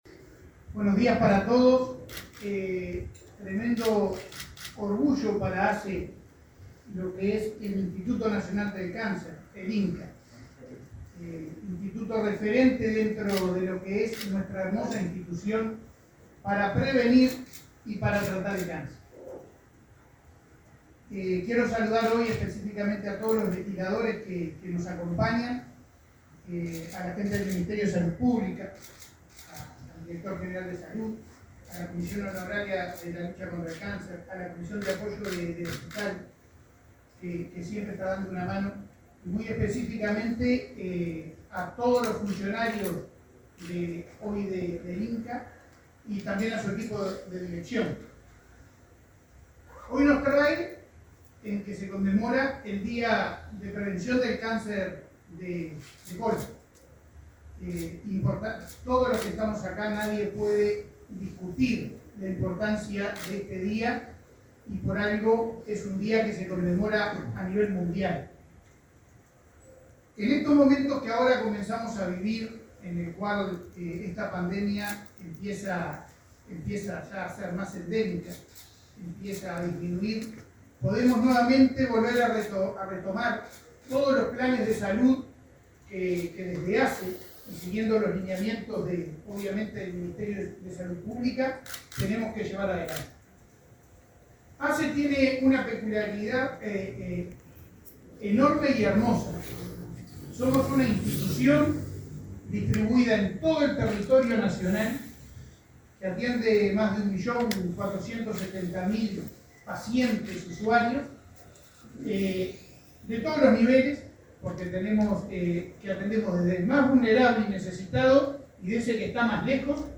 Instituto Nacional del Cáncer inauguró sector para patología digestiva 31/03/2022 Compartir Facebook X Copiar enlace WhatsApp LinkedIn El presidente de ASSE, Leonardo Cipriani, y el director general del MSP, Miguel Asqueta, participaron de la inauguración de un sector en el Centro de Cáncer Digestivo del Instituto Nacional del Cáncer.